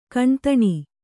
♪ kaṇtaṇi